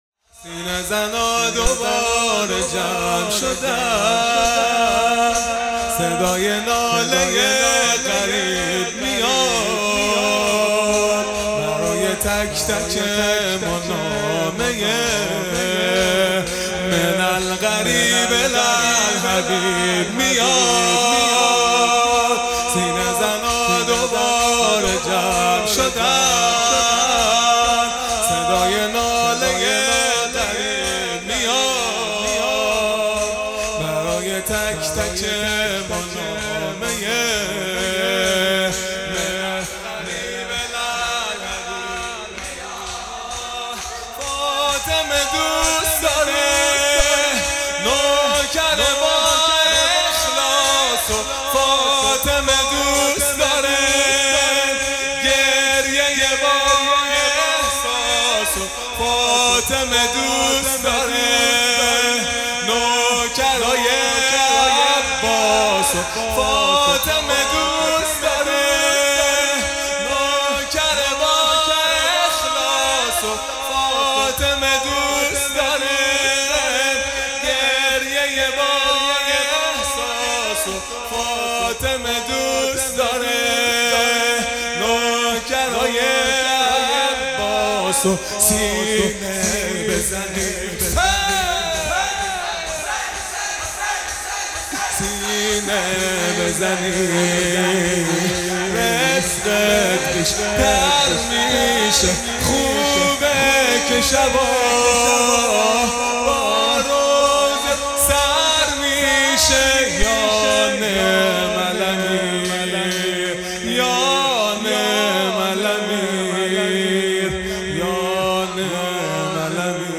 خیمه گاه - هیئت بچه های فاطمه (س) - شور | سینه زنا دوباره جمع شدن | پنج شنبه ۲۵ دی ۹۹